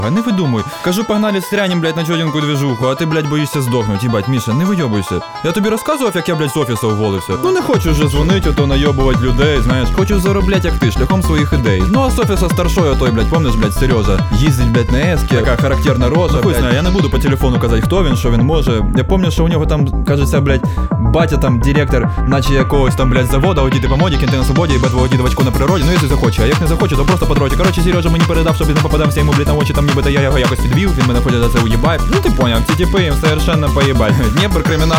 Жанр: Фолк / Русские